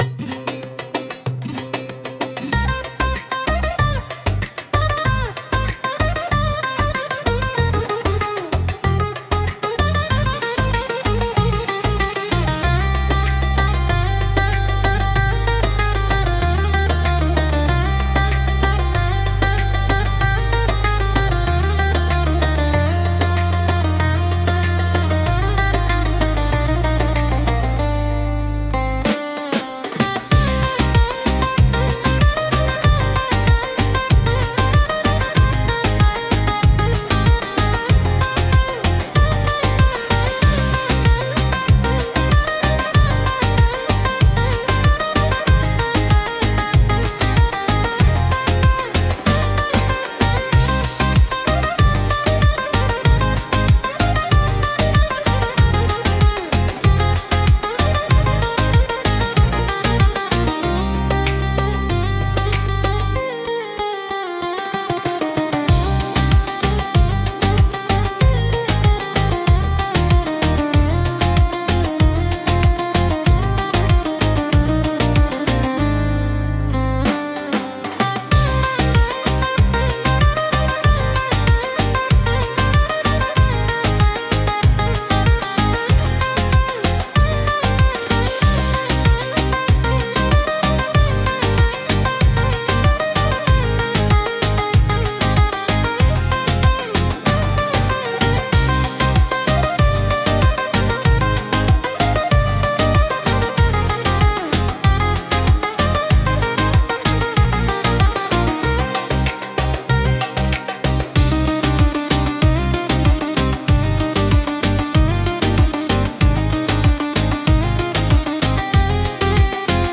独特のメロディとリズムで聴く人の感覚を刺激します。